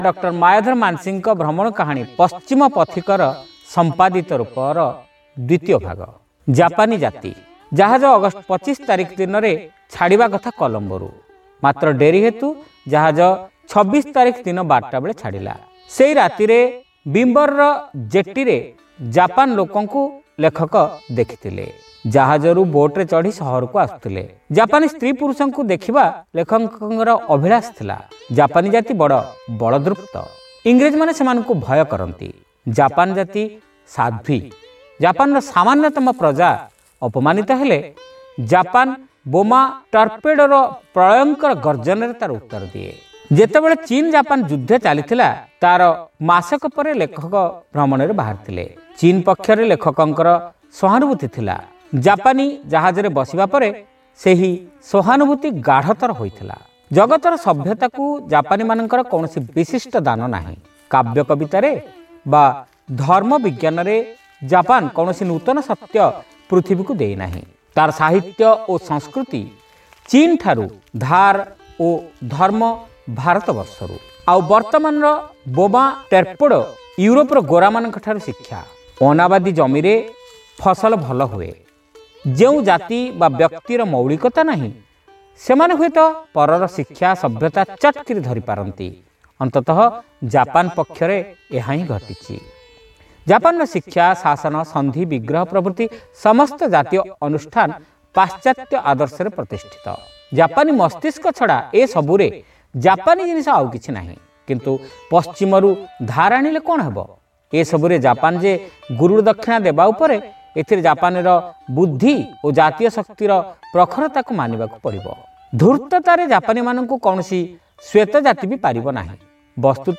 ଶ୍ରାବ୍ୟ ଗଳ୍ପ : ପଶ୍ଚିମ ପଥିକର ସମ୍ପାଦିତ ରୂପ (ଦ୍ୱିତୀୟ ଭାଗ)